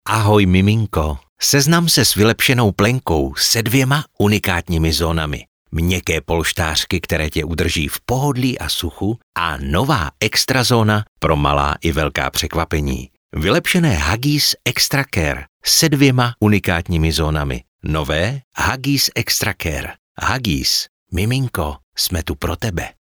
nabízím profesionální, příjemný mužský hlas ošlehaný téměř 30 lety zkušeností u mikrofonů v rádiích i studiích.
Pracuji ve svém nahrávacím studiu nebo po dohodě kdekoliv jinde.